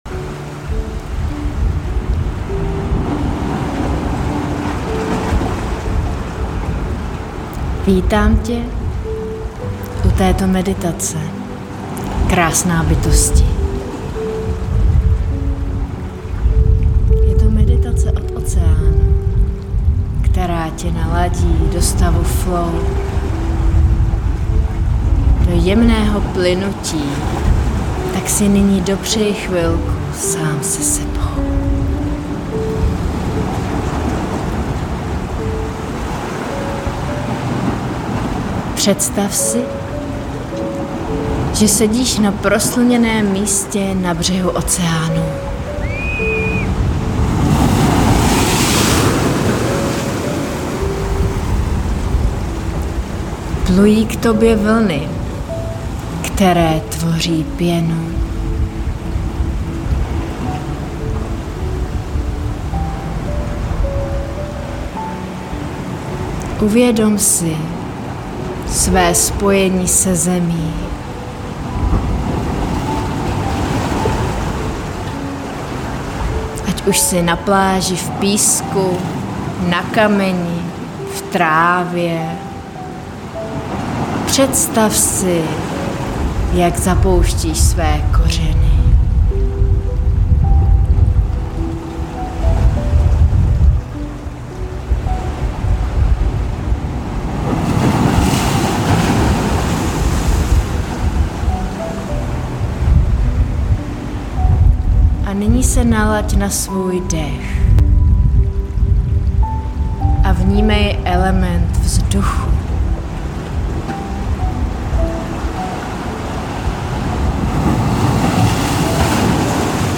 Meditaci jsem nacítila a nahrála na Novoluní při ranním přílivu na břehu Atlantského oceánu. Prociť tu energii v ní obsaženou a oddej se flow za autentického šumění oceánu.